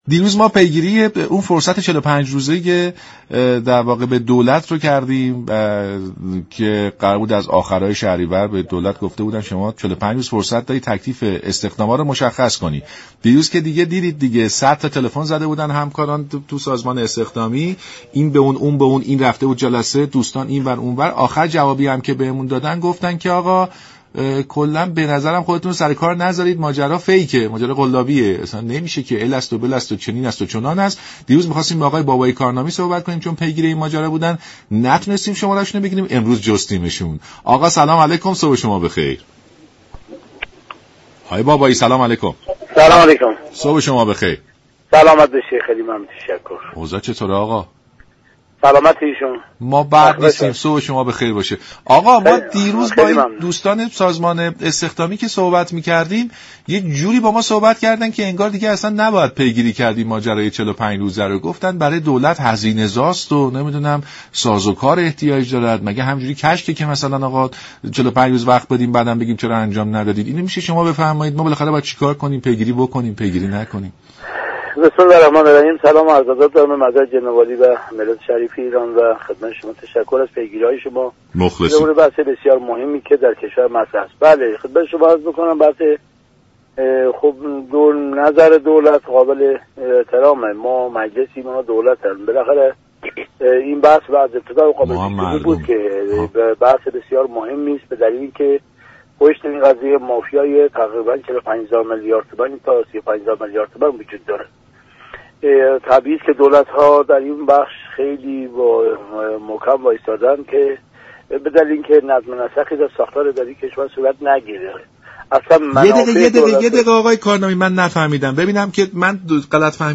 به گزارش شبكه رادیویی ایران، علی بابایی كارنامی نماینده مردم ساری در مجلس شورای اسلامی و نایب رئیس اول كمیسیون اجتماعی مجلس در برنامه «سلام صبح بخیر» رادیو ایران به بحث تعیین تكلیف وضعیت استخدامی كاركنان دولت اشاره كرد و گفت: تعیین و تكلیف وضعیت استخدامی كشور گرچه موضوع بسیار مهمی است اما در پشت این ماجرا مافیای 45 هزار میلیارد تومانی وجود دارد و به نظر می دهد این مافیا اجازه نمی دهد نظم و مقرراتی در چارچوب اداری كشور صورت گیرد.